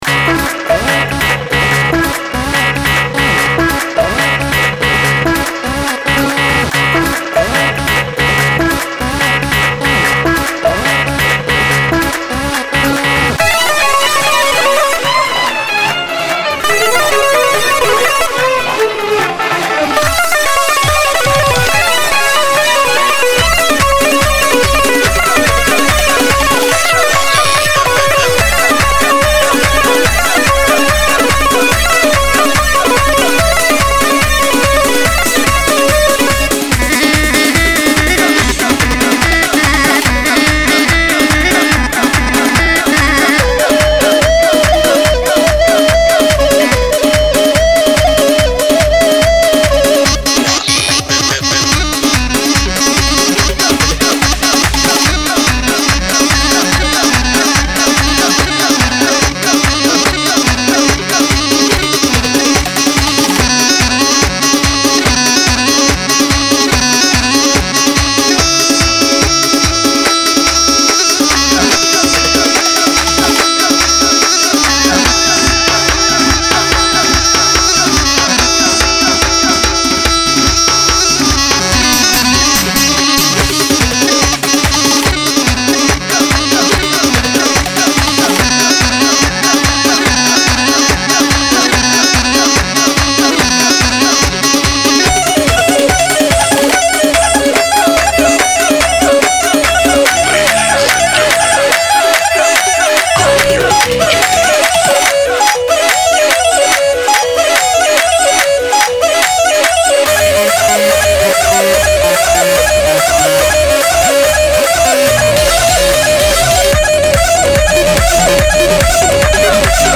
بمب مراسمات کوردی
بسیا بسیار شاد، زیبا و هیجان آور
انواع ریتمهای کردی و فارسی
تنوع بسیار زیاد در لوپهای کردی.